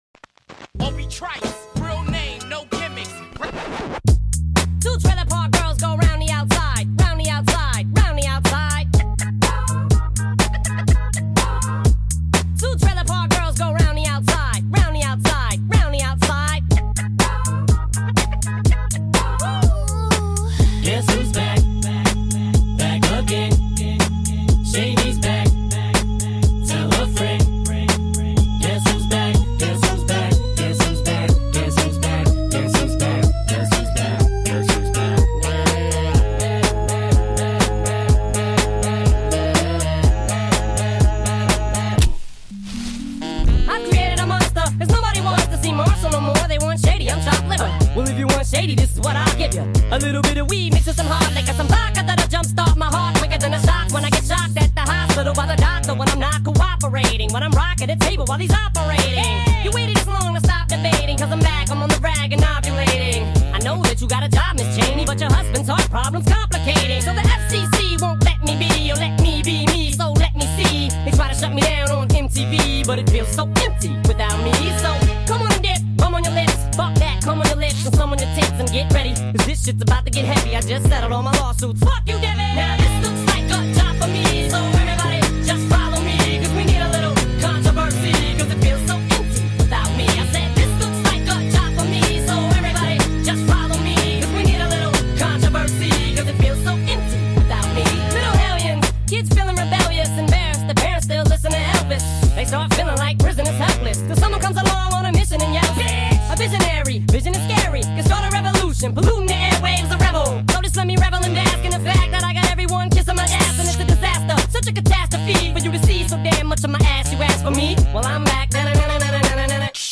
Enjoy the outtakes ✌🏻 . . Asian Beats airs every Monday, Thursday & Saturday on BBC West Midlands and Coventry & Warwickshire.